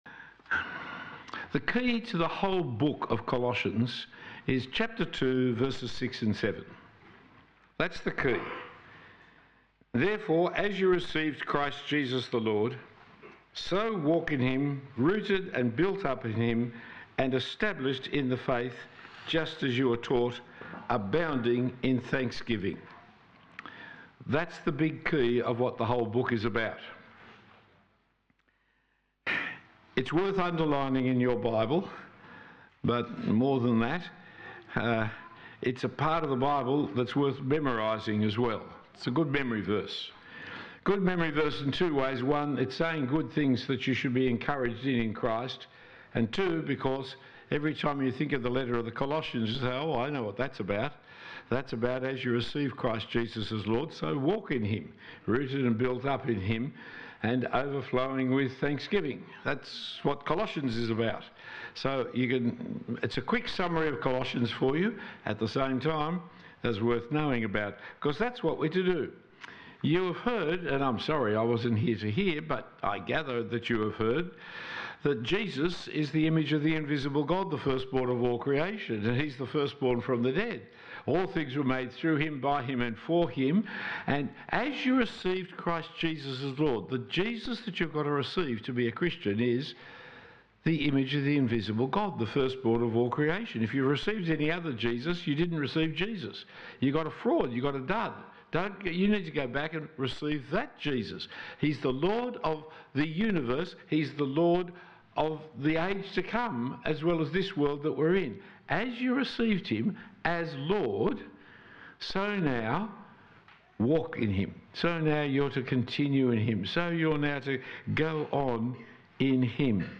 Talk 1 of 4 at the St Andrew’s Strathfield parish weekend away.